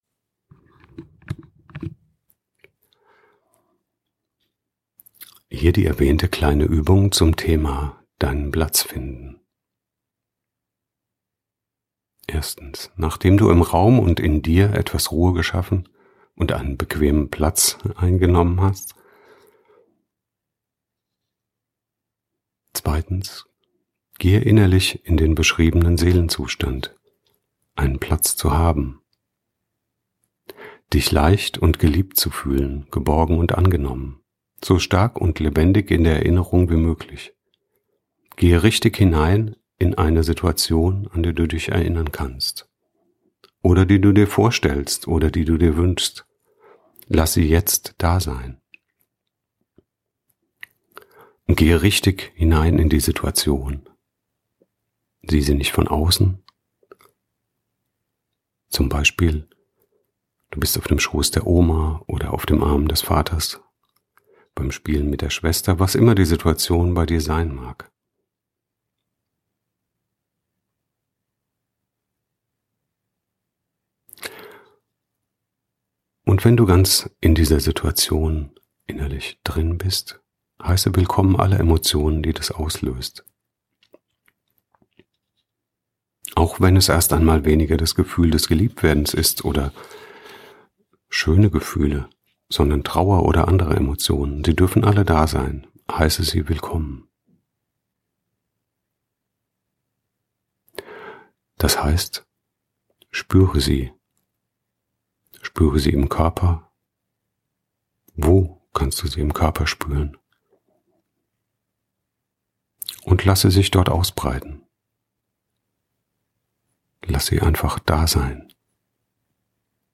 (Vorgesprochene Hörübung weiter unten)